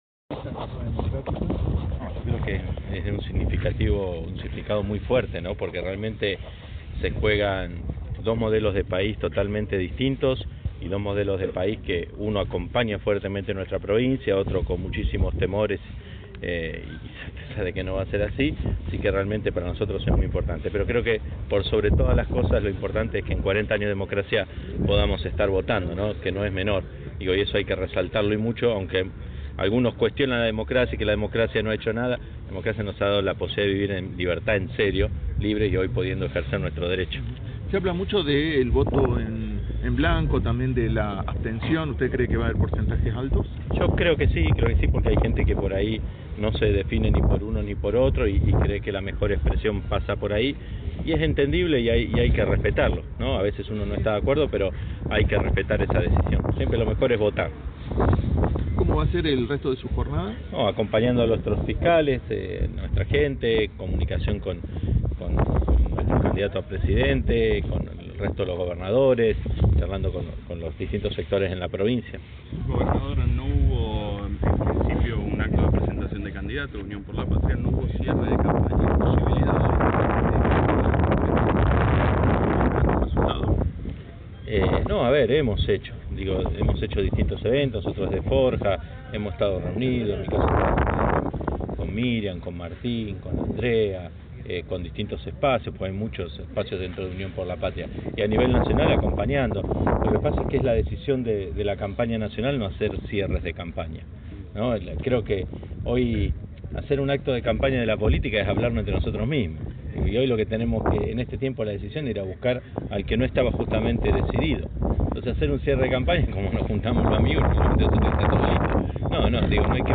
Rio Grande 19/11/2023.- El Gobernador de Tierra del Fuego AIAS, Gustavo Melella, emitió su voto temprano en la mañana en el Colegio Polivalente de Arte en la ciudad de Río Grande.